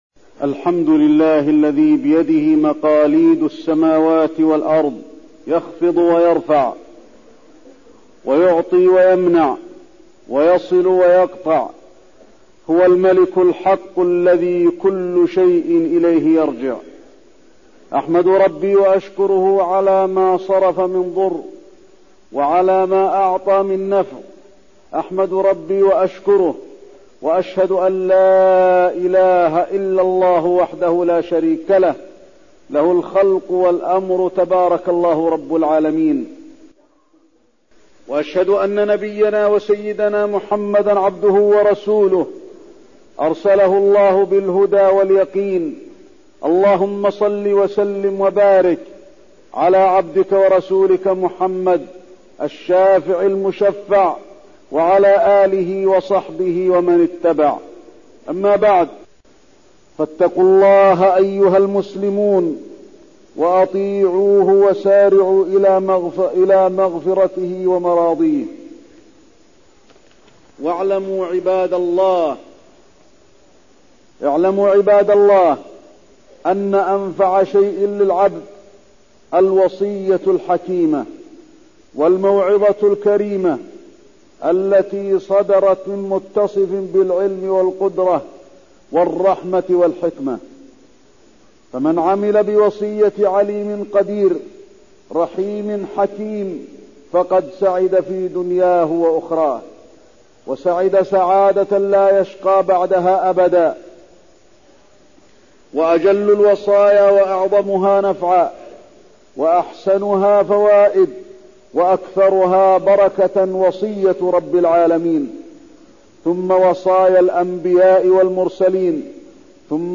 تاريخ النشر ٧ شعبان ١٤١٣ هـ المكان: المسجد النبوي الشيخ: فضيلة الشيخ د. علي بن عبدالرحمن الحذيفي فضيلة الشيخ د. علي بن عبدالرحمن الحذيفي وصية لقمان لإبنه